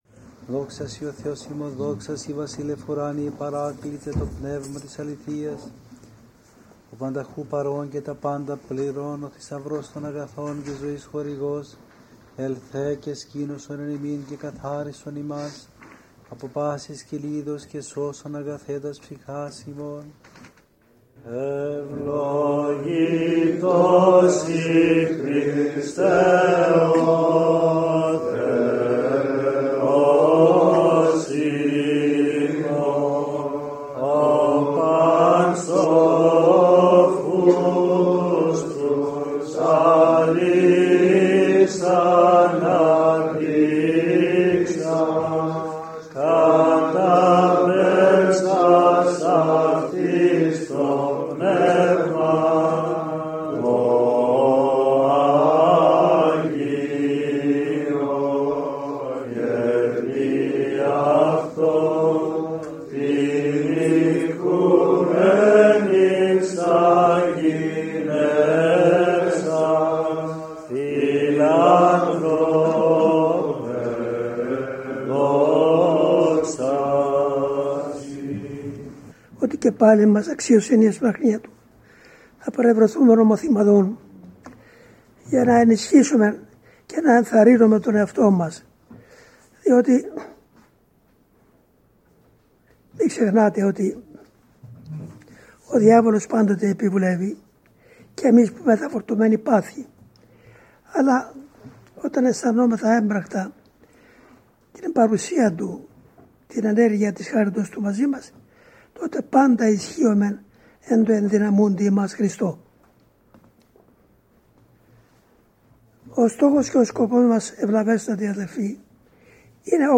17/11/2003 - Σύναξη της αδελφότητος